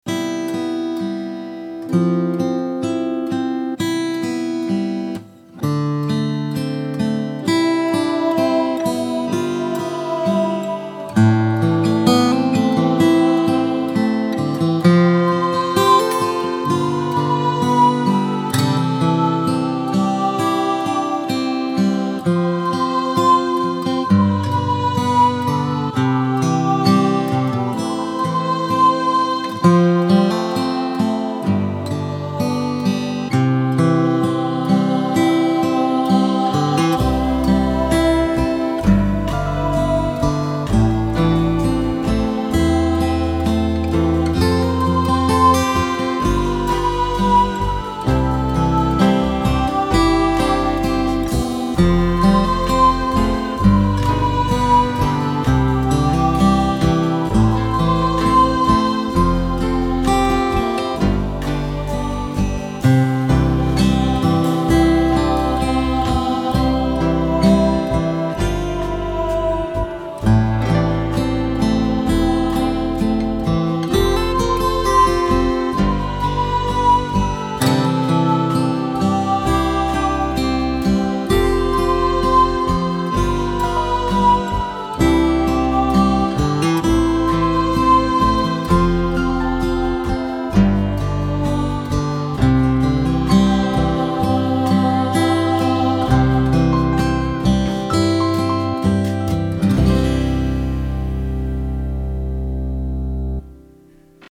chants
traditional Argentinian melody
It is a lovely melody though